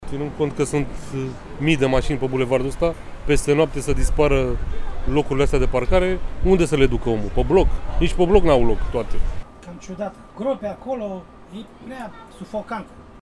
Unii șoferi spun că nu știu ce se va întâmpla cu mașinile parcate pe marginea bulevardului, în timp ce conducătorii de autobuze susțin că banda este sufocantă: